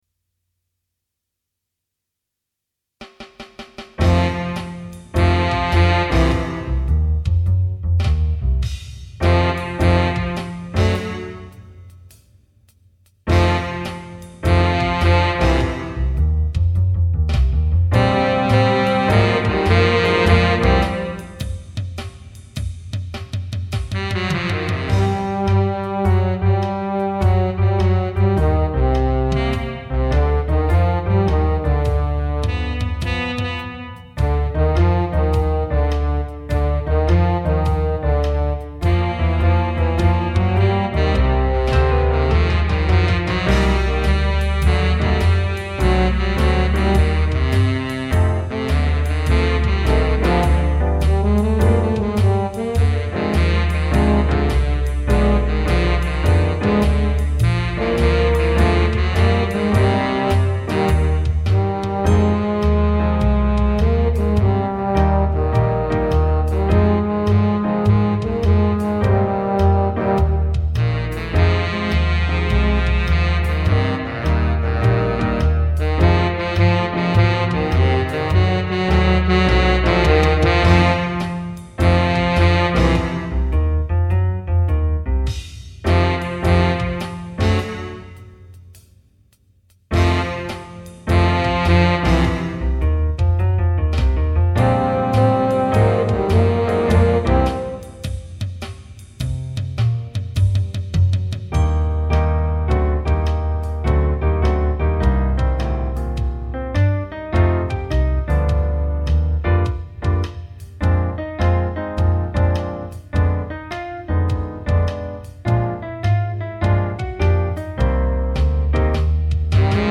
minus Instrument 1